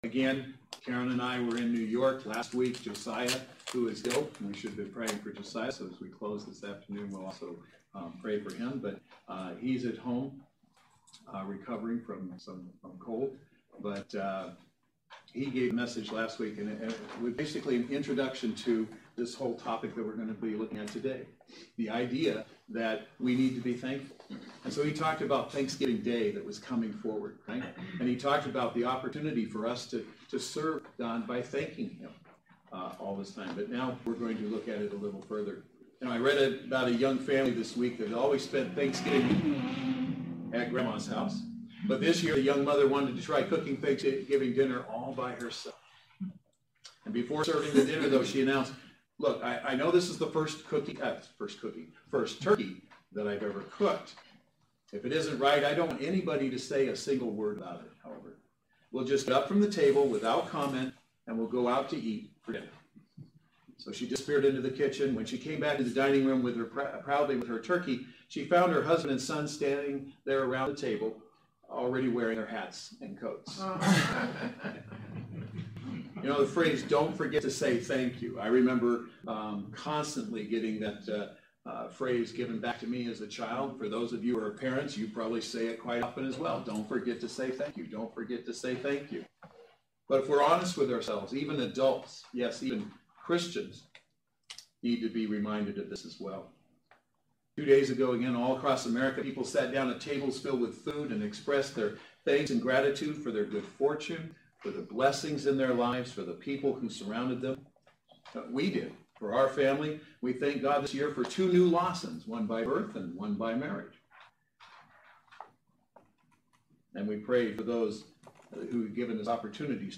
Passage: Psalm 100:1-5 Service Type: Saturday Worship Service